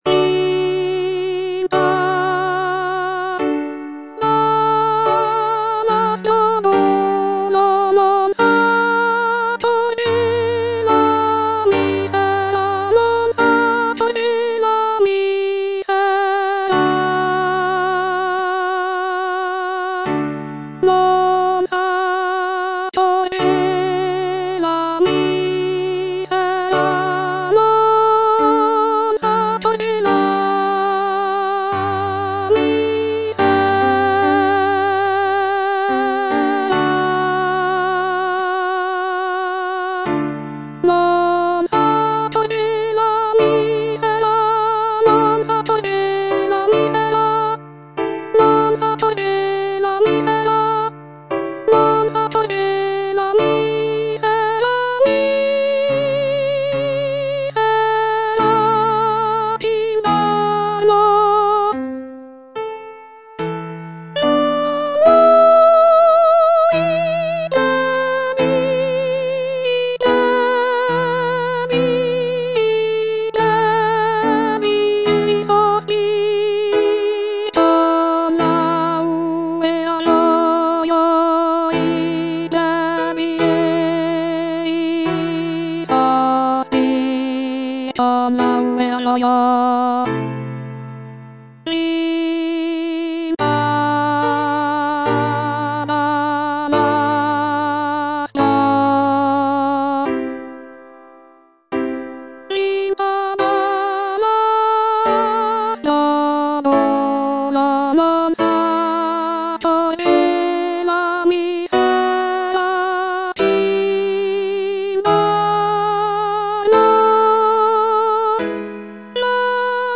soprano II) (Voice with metronome